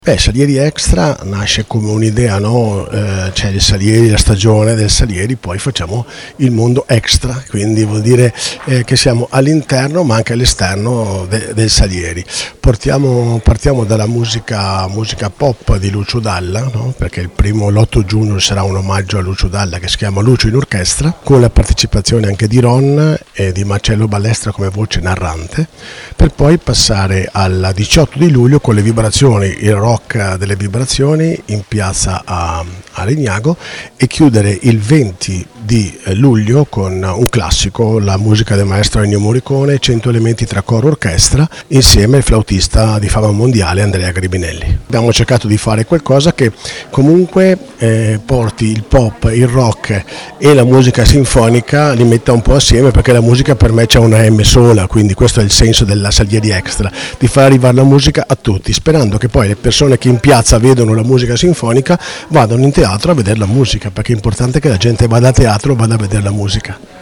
Ecco le dichiarazioni raccolte nella giornata di presentazione